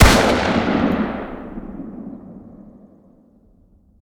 fire-dist-357mag-pistol-ext-04.ogg